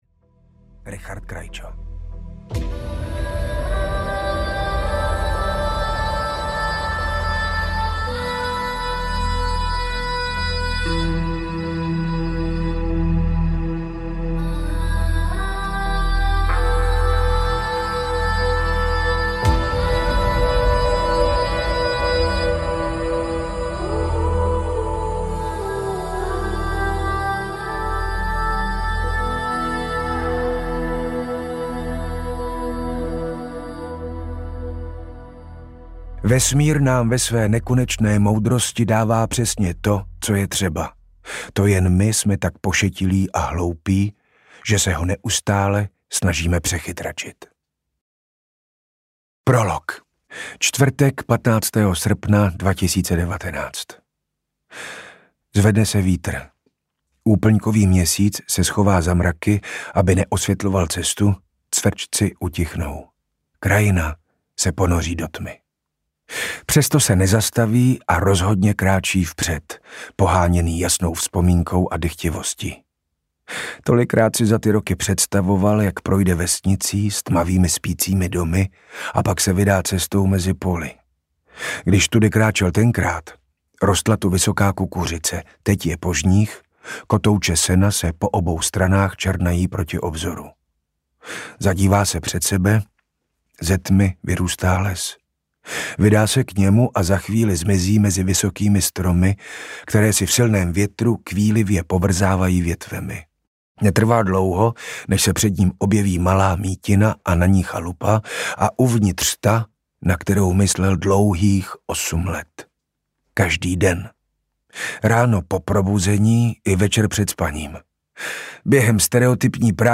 Čarodějky audiokniha
Ukázka z knihy
• InterpretRichard Krajčo